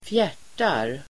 Ladda ner uttalet
fjärta verb (vardagligt), fart [informal]Grammatikkommentar: A &Uttal: [²fjärt:ar] Böjningar: fjärtade, fjärtat, fjärta, fjärtarSynonymer: fisa, prutta, släppa sigDefinition: fisa